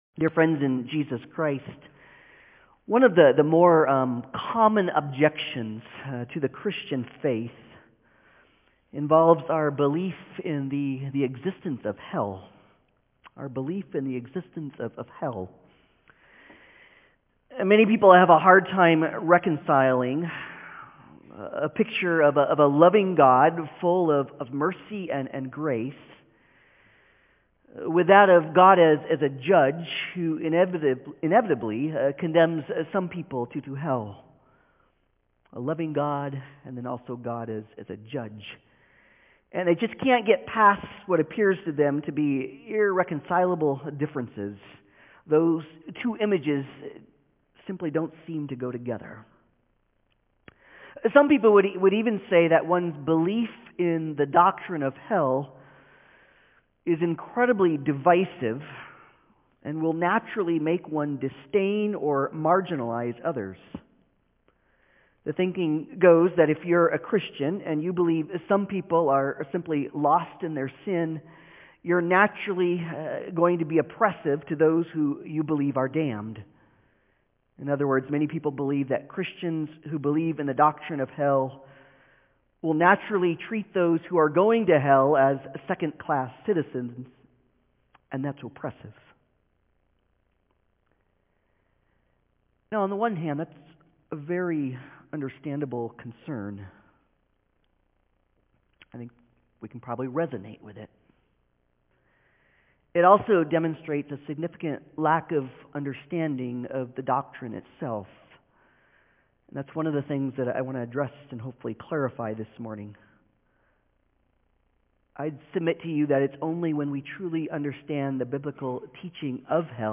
Passage: Luke 16:19-31 Service Type: Sunday Service